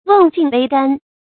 瓮尽杯干 wèng jìn bēi gān
瓮尽杯干发音
成语注音 ㄨㄥˋ ㄐㄧㄣˋ ㄅㄟ ㄍㄢˋ